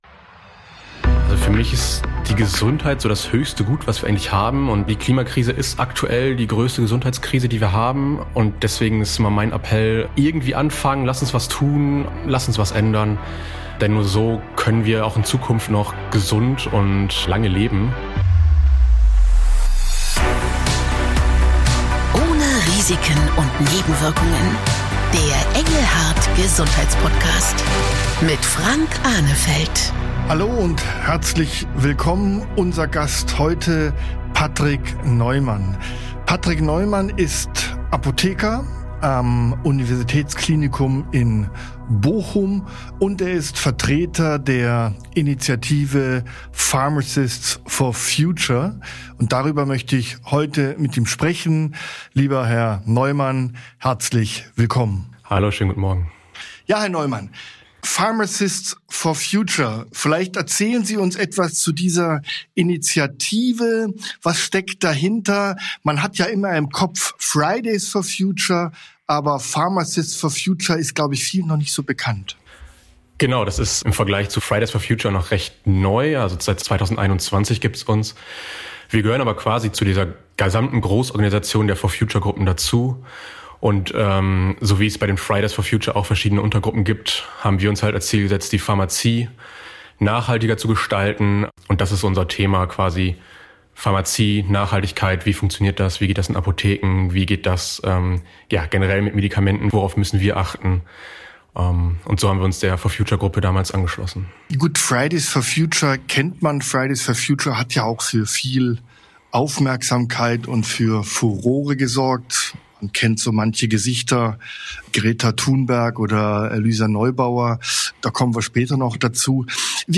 Wie müsste sich das Gesundheitswesen verändern, um nachhaltiger zu werden, was können Apothekerinnen und Apotheker konkret tun, um klimafreundlicher zu arbeiten? Und wie können sich Arzneimittelhersteller stärker für die Umwelt engagieren? Antworten hier in OHNE RISIKEN UND NEBENWIRKUNGEN - der Podcast für Gesundheitsmacher.